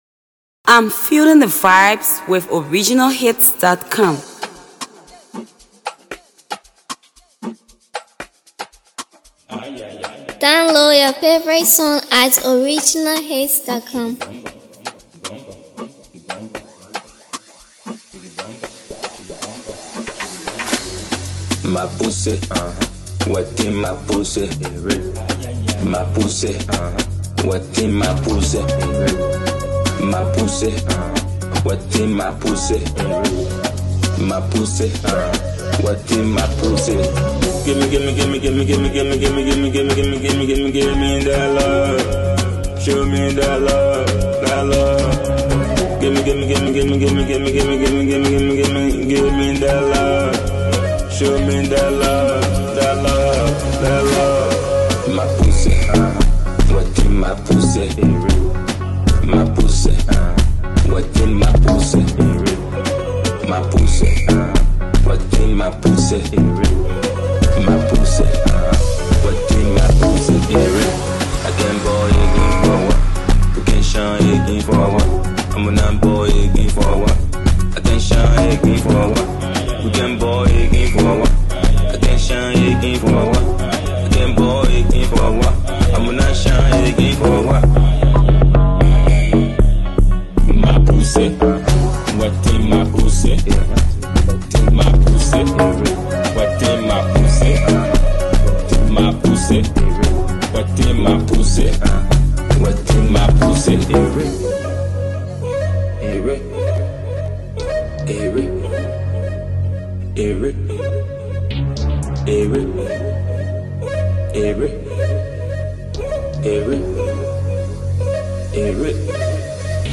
It’s a catchy, danceable hit.